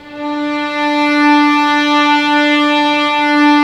Index of /90_sSampleCDs/Roland L-CD702/VOL-1/STR_Vlns 6 mf-f/STR_Vls6 mf amb